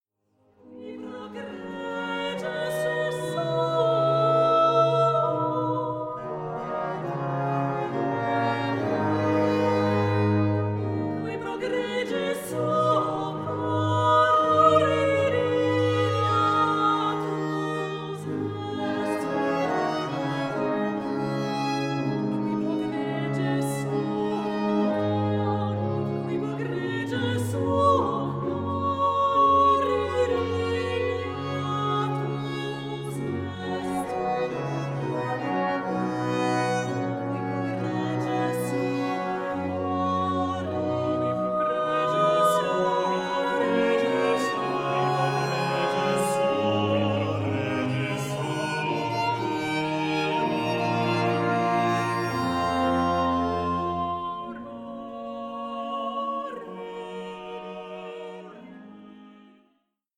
Instrumentalisten